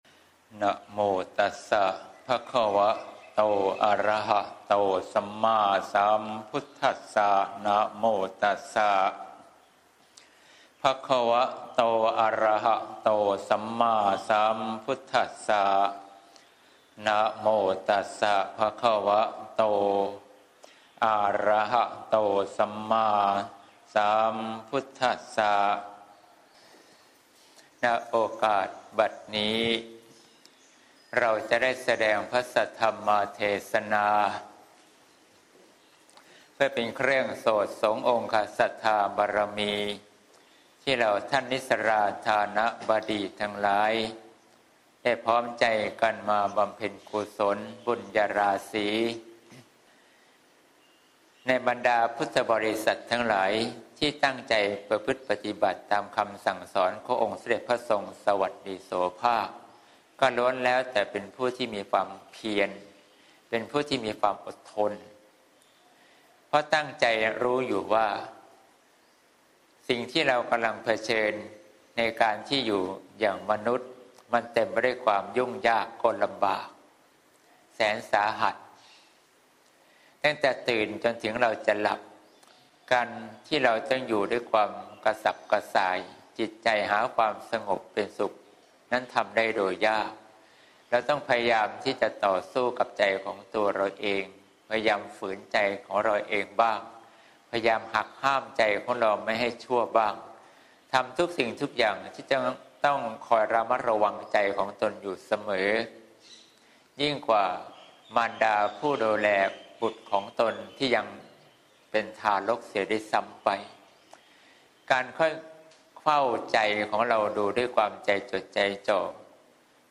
เทศน์ ทานที่พร้อมด้วยเมตตา (เสียงธรรม ๒๙ มี.ค. ๖๘)